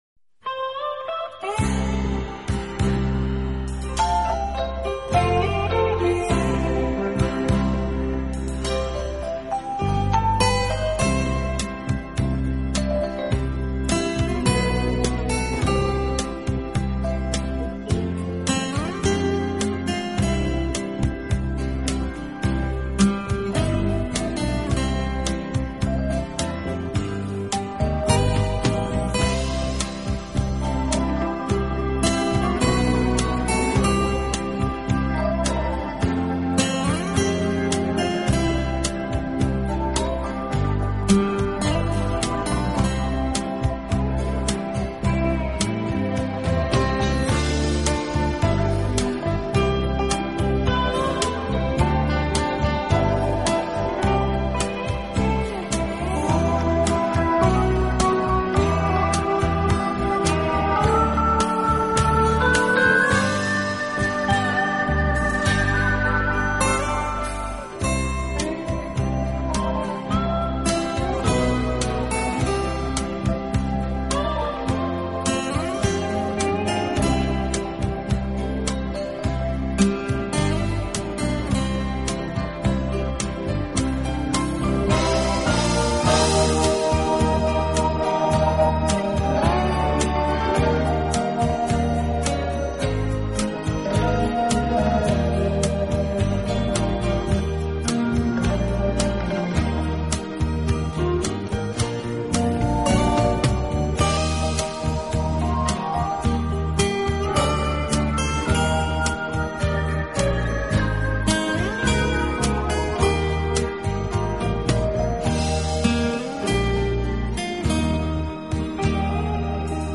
是乐队演奏的主要乐器，配以轻盈的打击乐，使浪漫气息更加浓厚。